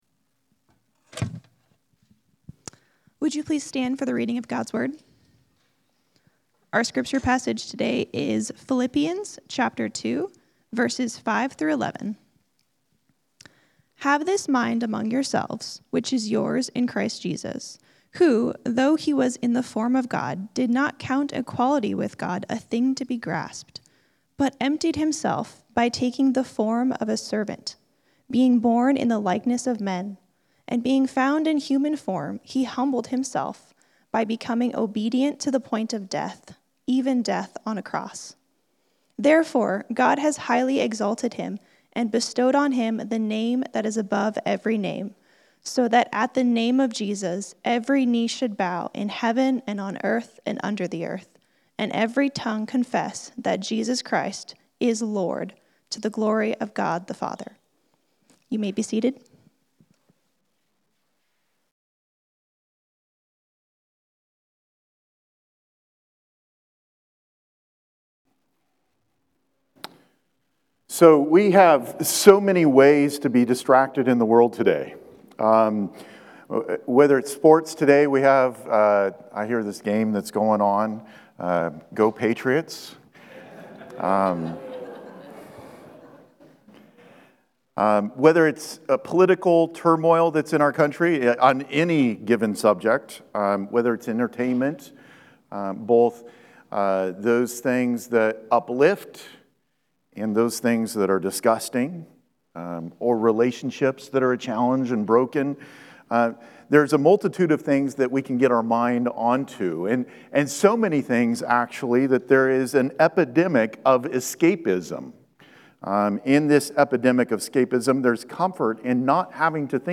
Sermons | Grace Church - Pasco